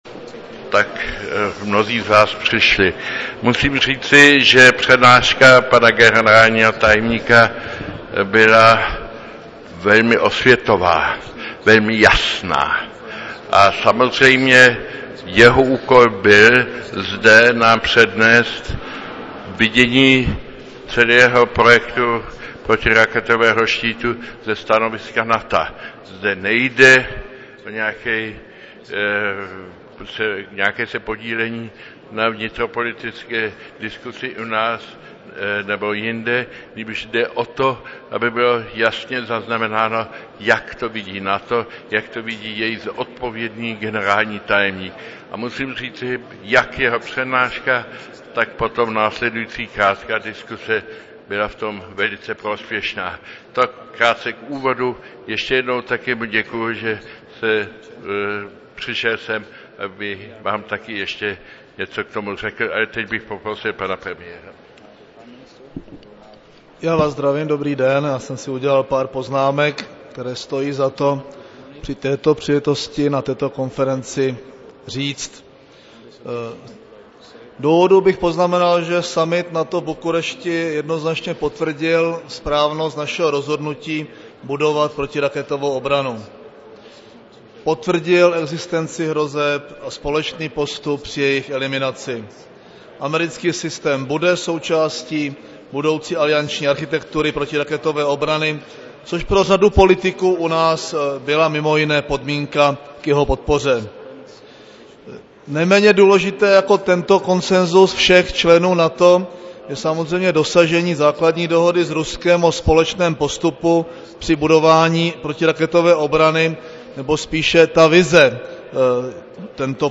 Zvukový záznam části konference (mp3, 1,5MB)